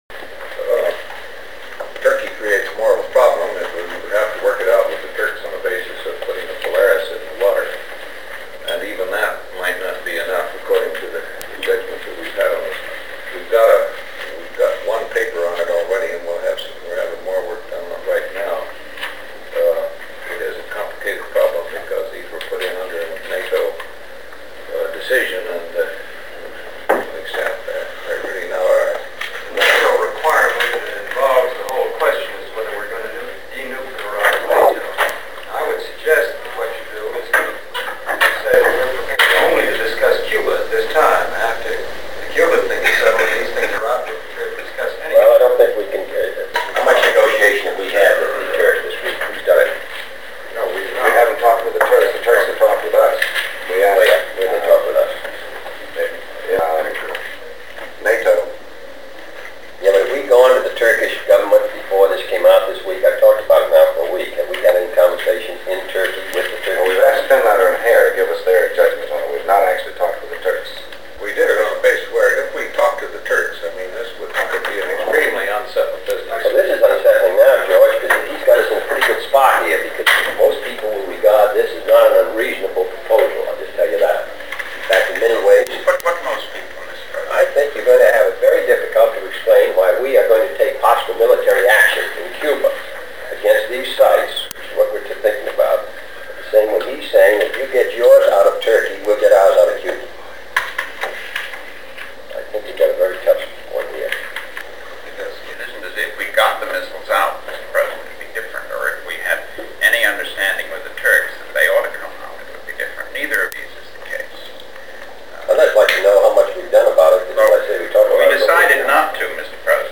ExComm meeting, October 27, 1962